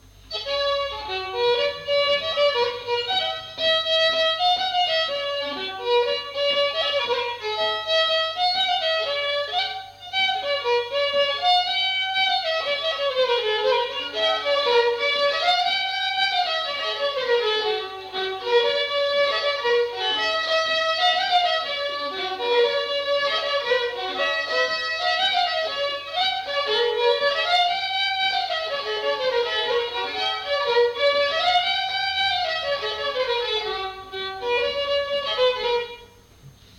Aire culturelle : Petites-Landes
Genre : morceau instrumental
Instrument de musique : violon
Danse : rondeau
Notes consultables : 2 violons.